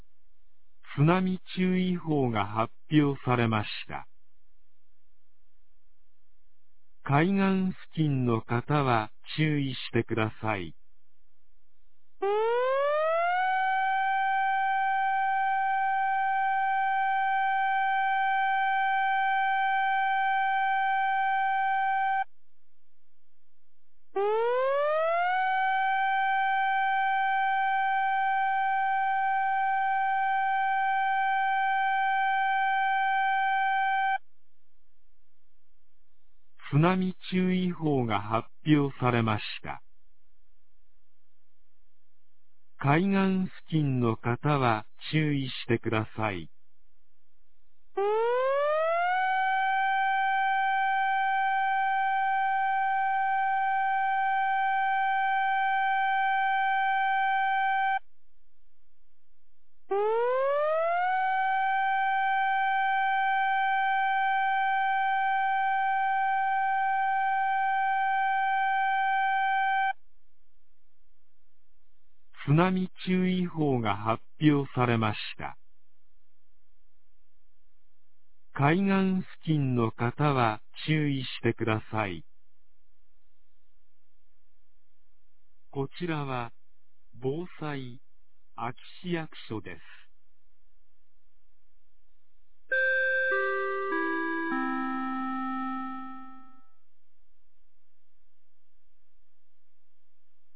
2023年12月02日 23時58分に、安芸市より全地区へ放送がありました。